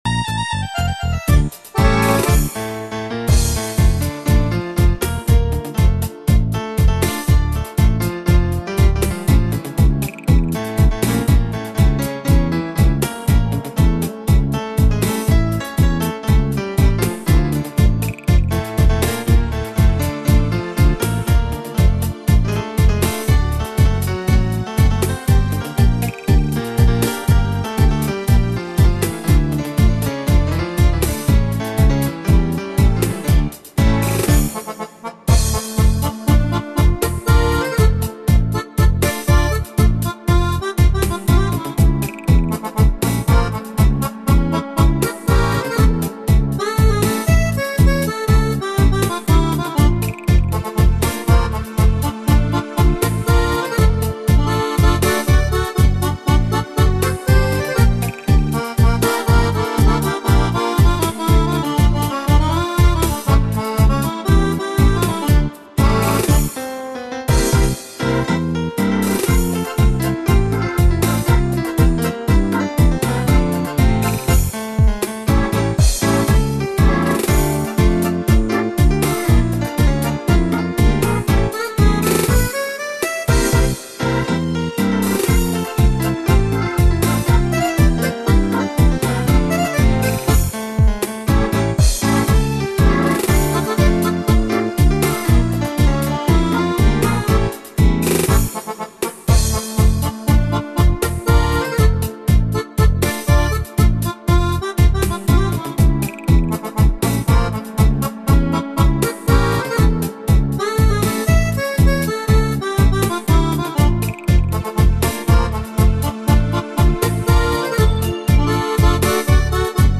аргентинское танго.